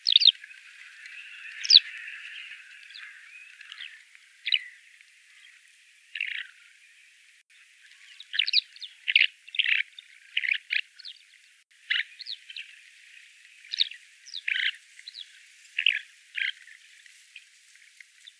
Alauda arvensis - Skylark - Allodola